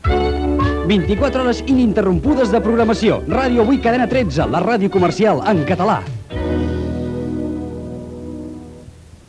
Indicatiu de l'emissora
FM